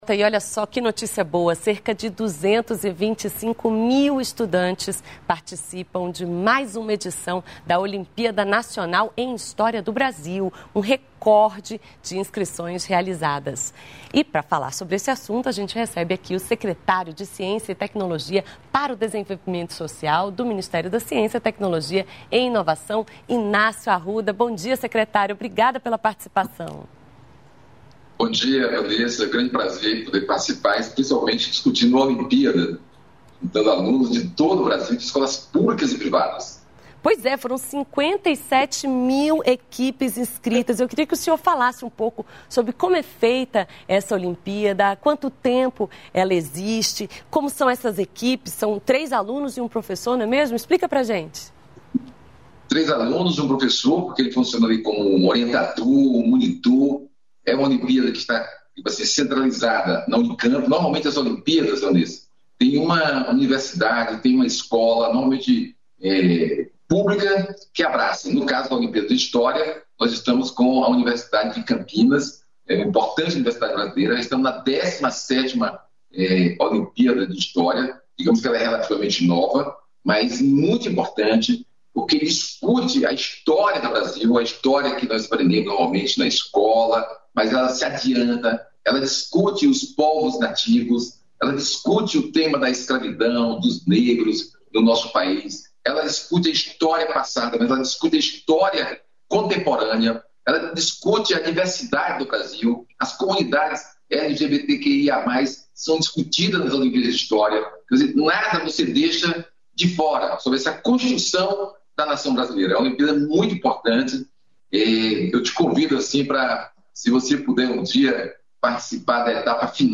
Brasil em Dia - Entrevista
O diretor-presidente da Companhia Nacional de Abastecimento, Edegar Pretto, explica como o programa tem ajudado na recuperação financeira dos pequenos produtores e na geração de renda nas áreas rurais.